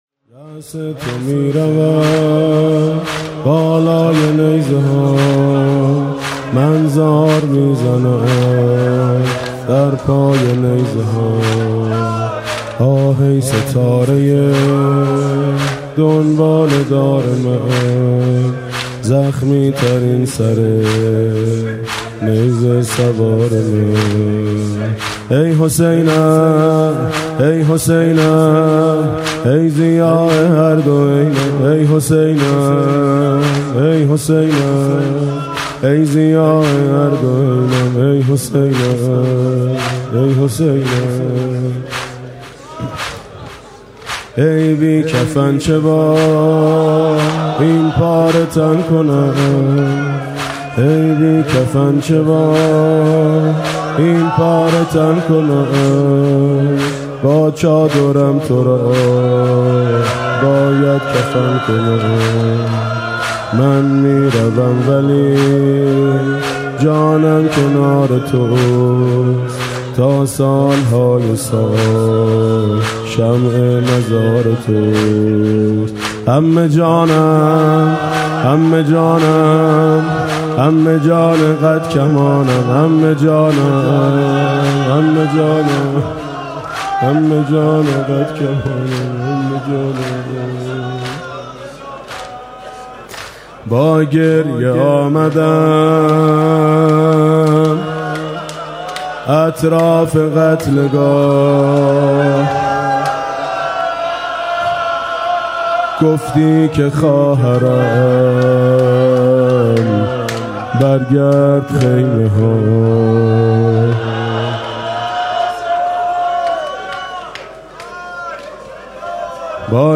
راس تو می‌رود، بالای نیزه‌ها (شور)